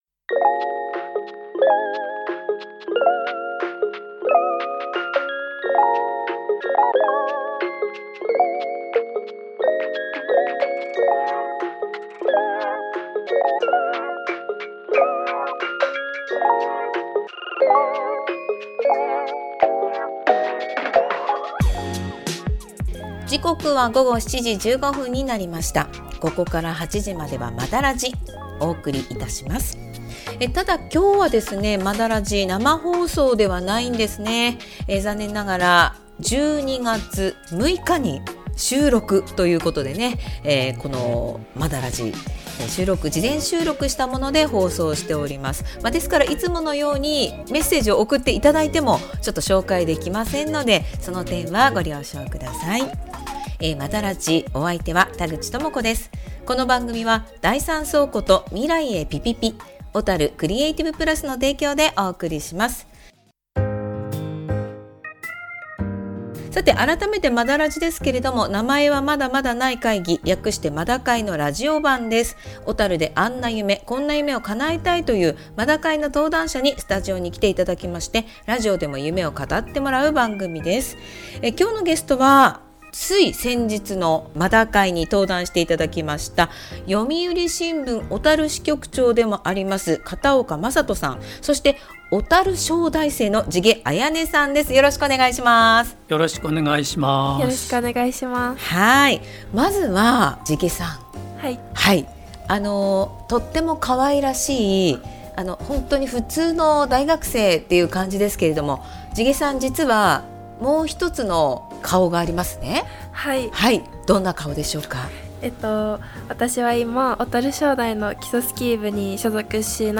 今月は、事前収録でお送りした「まだラジ」。